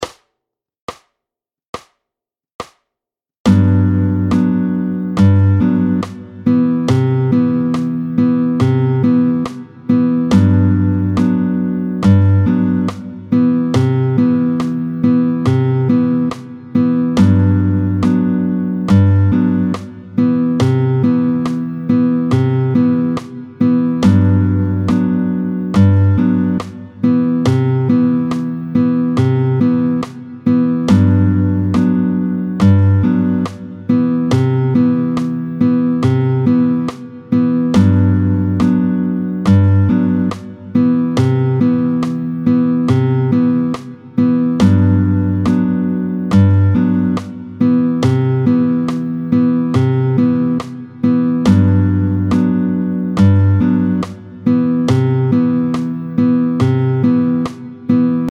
30-04 Song for Baden, tempo 70